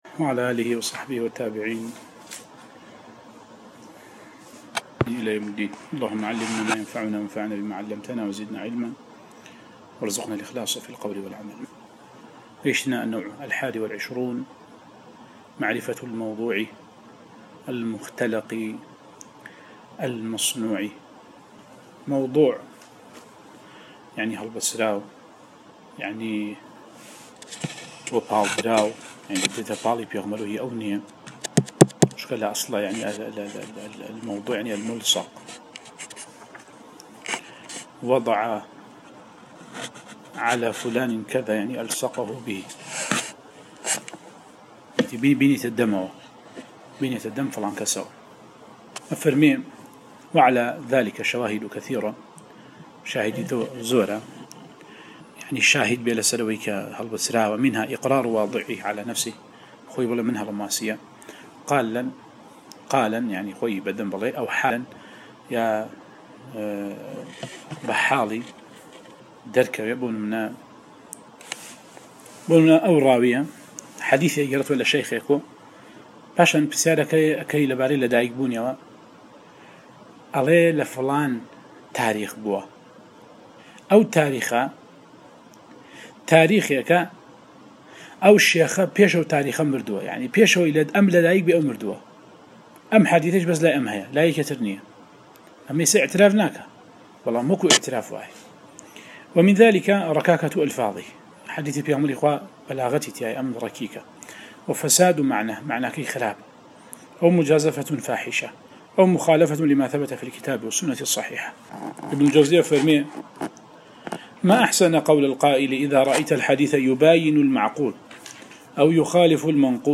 القراءة والتعليق على مواضع من الباعث الحثيث ـ 11